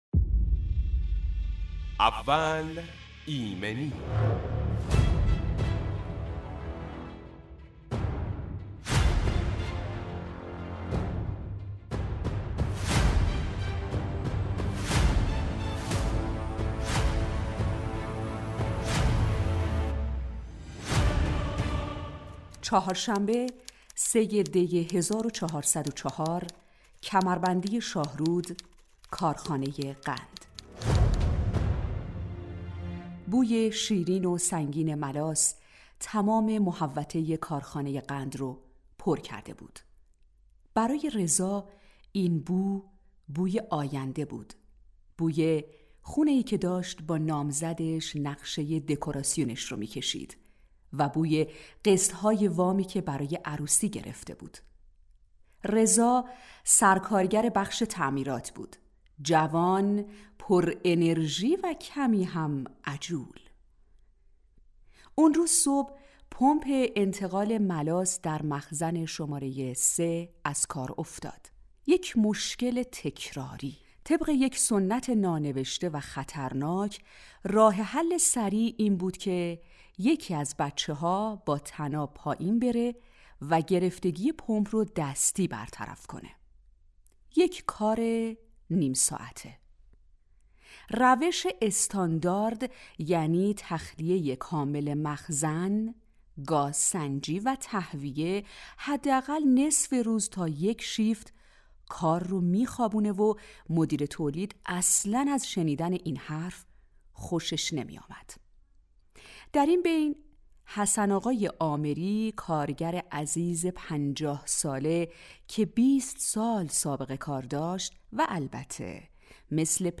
دارم می‌رسم بهش، اینجا خیلی دمه برنامه اول ایمنی به مدت ۱۵ دقیقه با حضور کارشناس متخصص آغاز و تجربیات مصداقی ایمنی صنعتی به صورت داستانی بیان می شود.